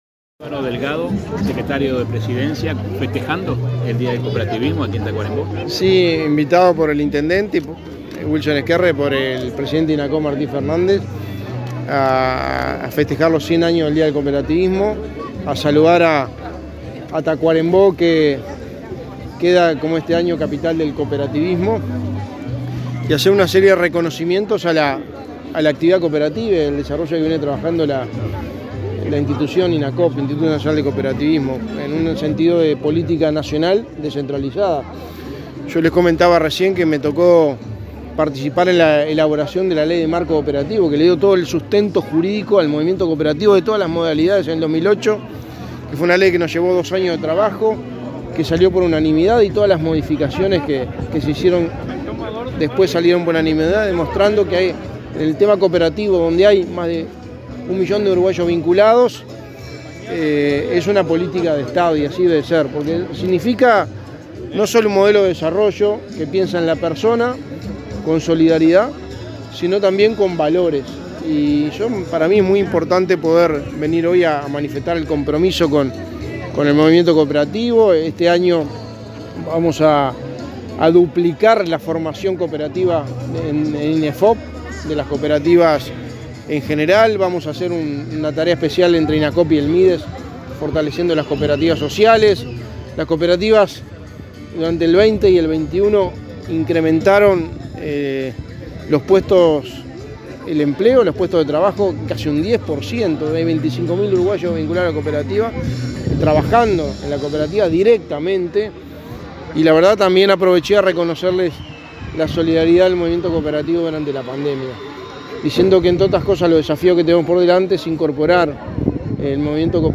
Declaraciones a la prensa del secretario de Presidencia, Álvaro Delgado
Luego, Delgado dialogó con la prensa.